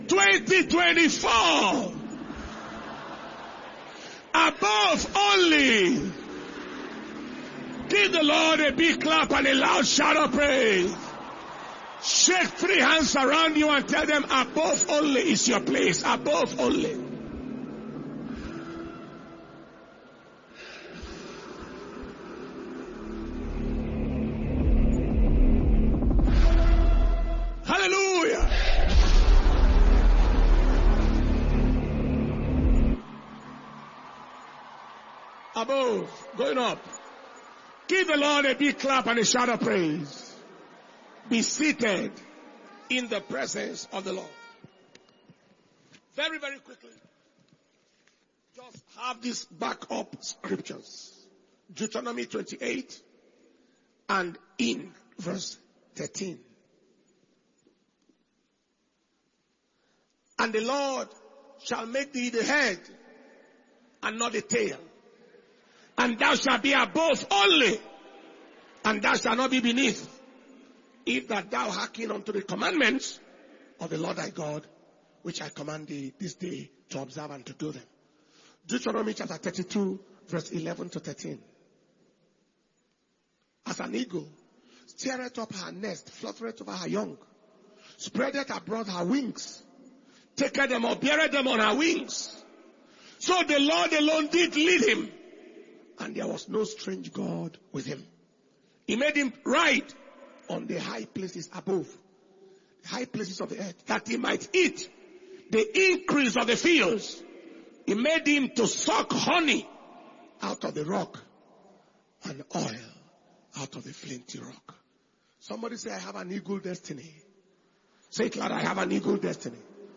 2023-2024 Crossover Service – Sunday, 31st December 2023